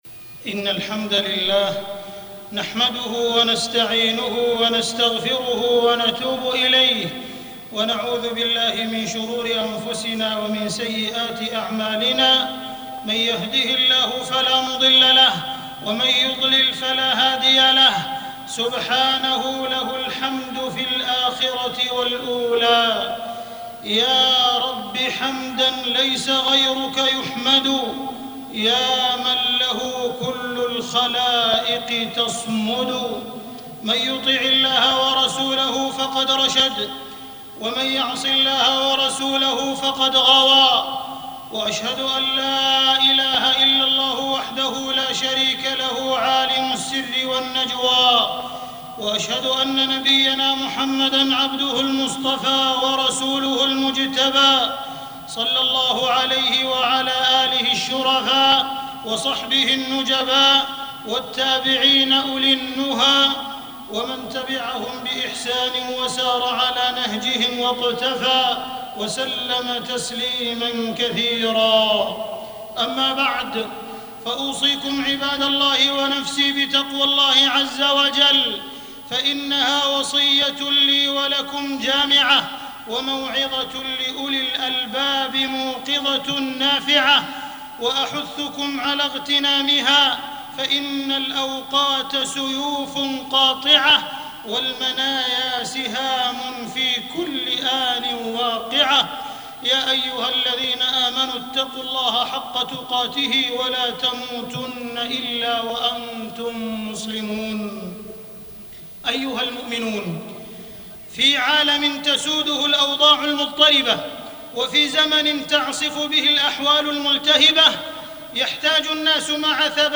تاريخ النشر ١ صفر ١٤٢٦ هـ المكان: المسجد الحرام الشيخ: معالي الشيخ أ.د. عبدالرحمن بن عبدالعزيز السديس معالي الشيخ أ.د. عبدالرحمن بن عبدالعزيز السديس يا قاتل الوطن برصاص وقلم